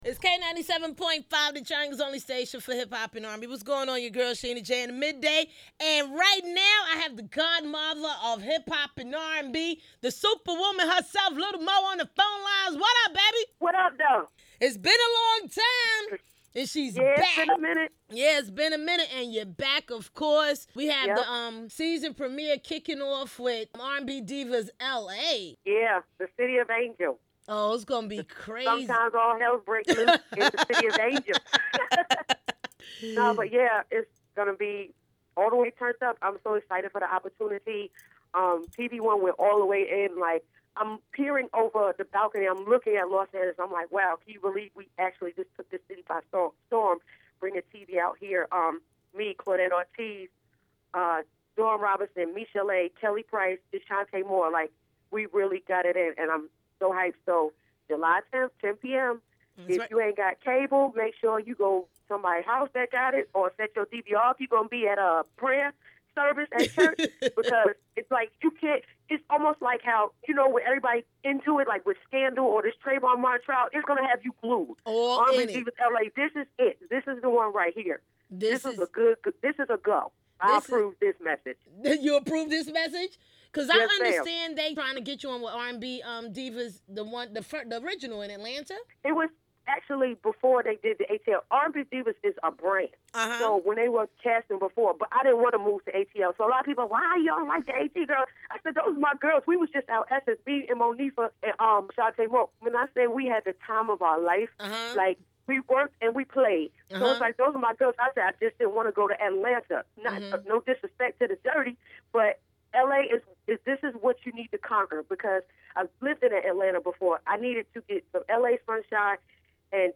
R&B Divas LA Lil Mo Interview
lil-mo-interview.mp3